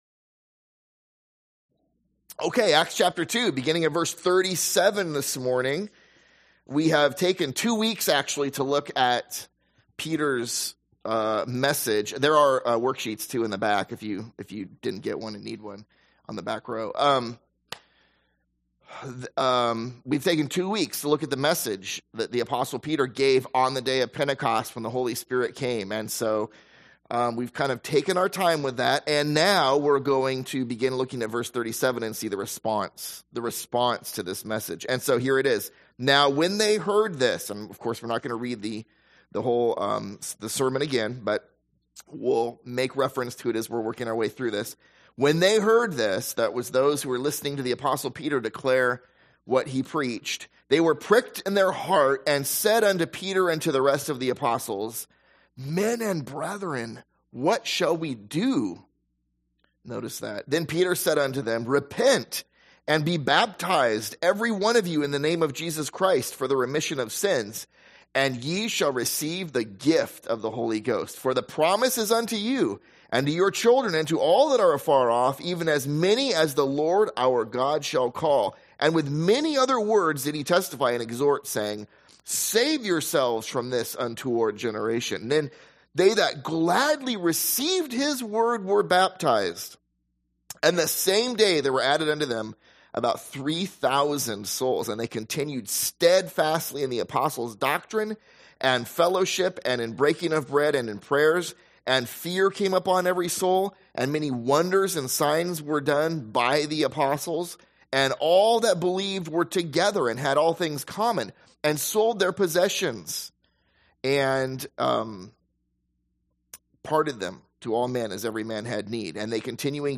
/ A Sunday School series on the book of Acts